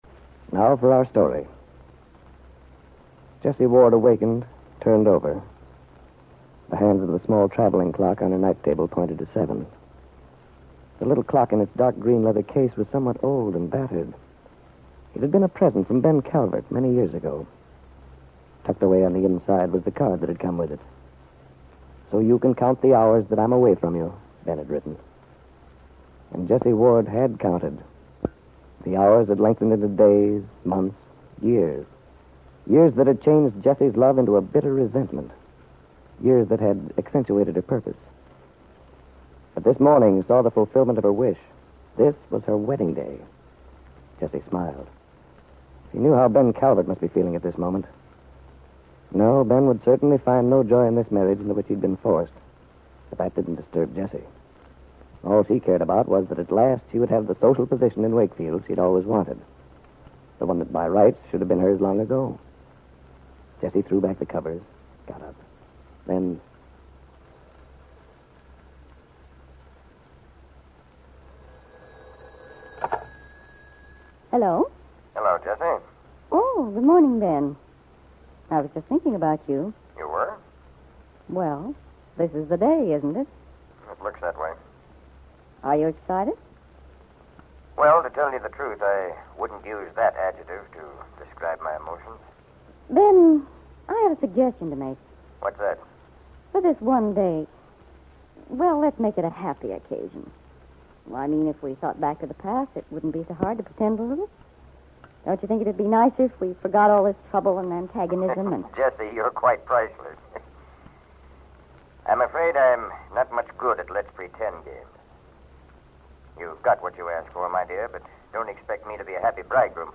Aunt Mary was a soap opera that follows a story line which appears to have been broadcast in 1945 and early 1946. The plot features a love triangle and involves a young woman in a failing marriage who pays an extended visit to "friends" in Los Angeles to conceal a dark secret from her father and others back in Wakefield.